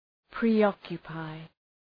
Προφορά
{prı’ɒkjə,paı}